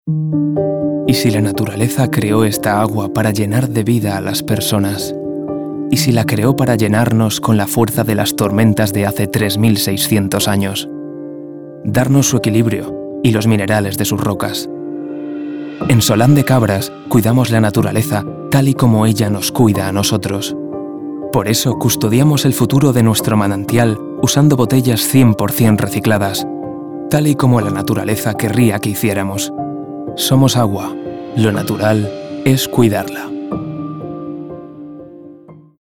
Comercial, Natural, Urbana, Cool, Cálida
Telefonía
He is characterized by having a special register that can lead to different timbres and tones, and having a voice with a fairly wide tonal range.